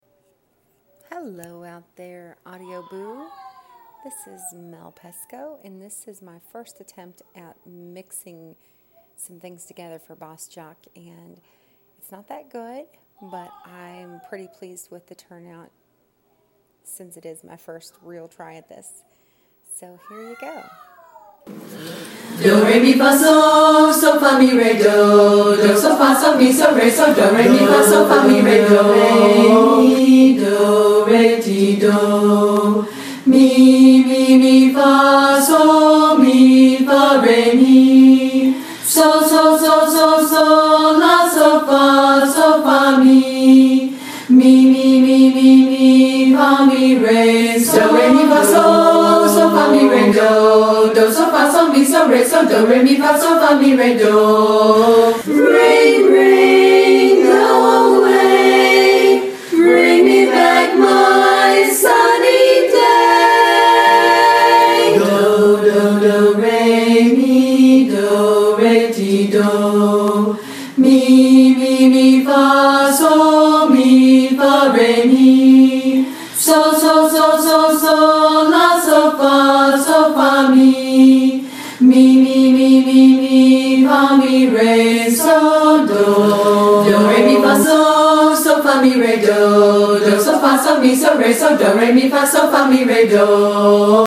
bossjock barbershop practice